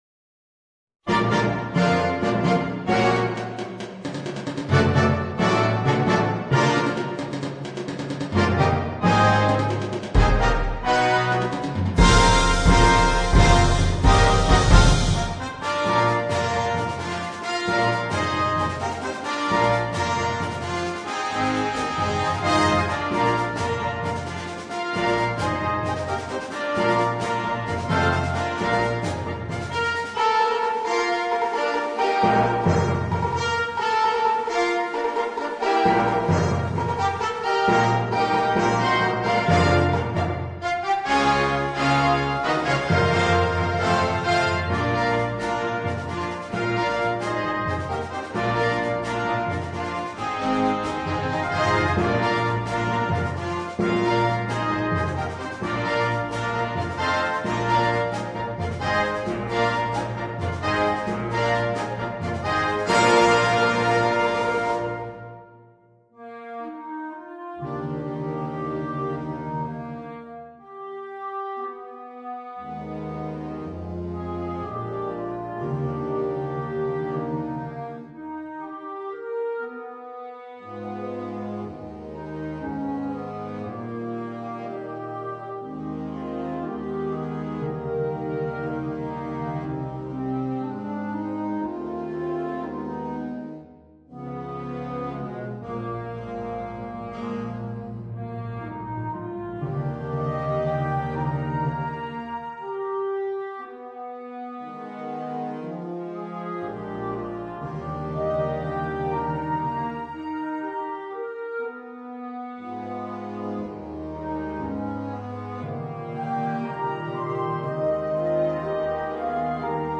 Overture for symphonic band